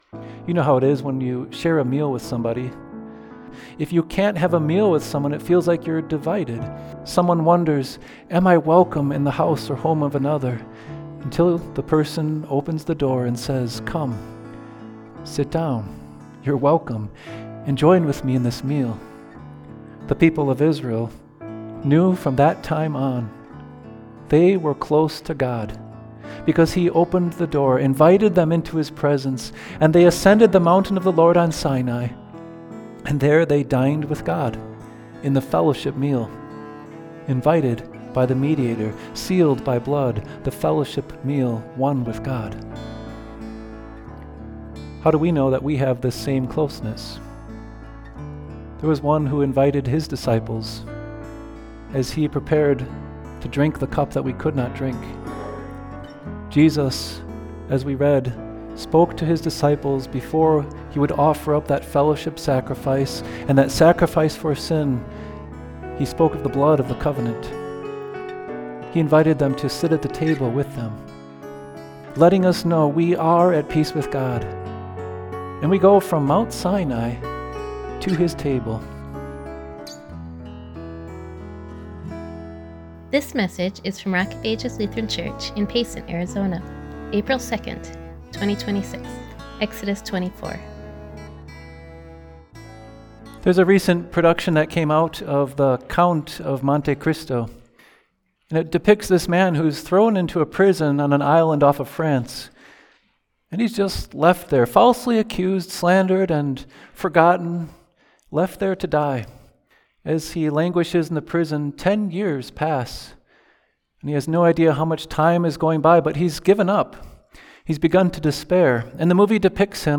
Exodus 24:1-11 ● April 2, 2026 ● Series for Holy Week ● Listen to Sermon audio